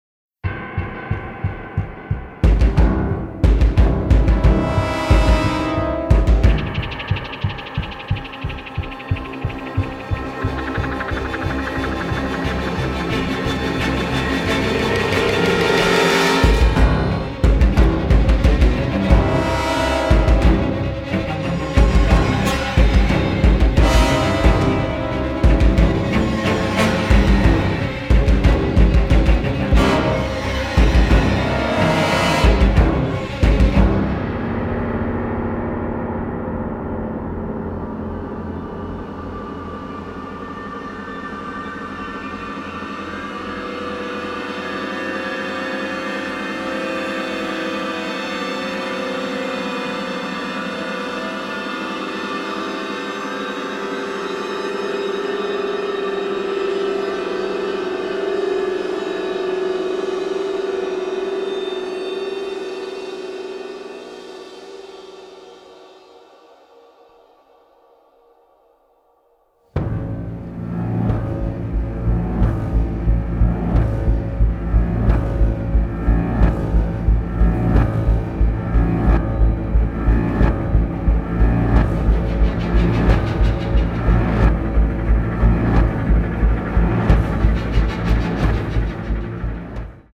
original motion picture score